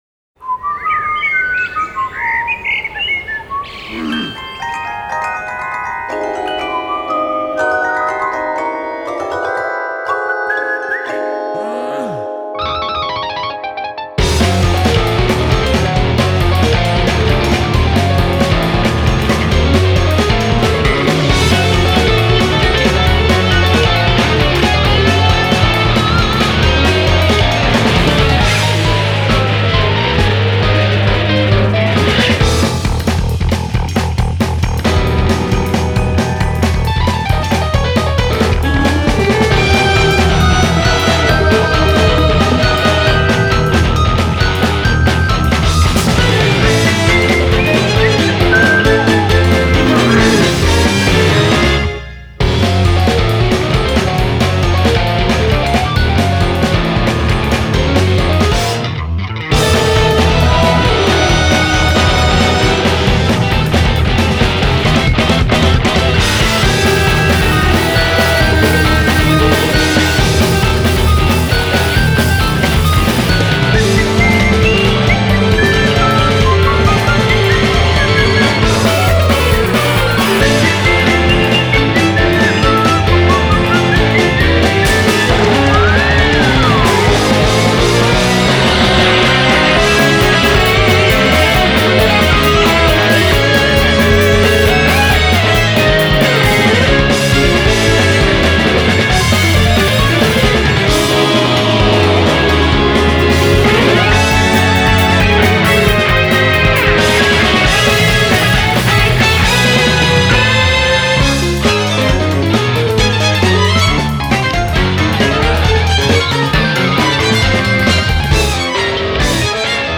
BPM110-135
Audio QualityPerfect (High Quality)
instrumental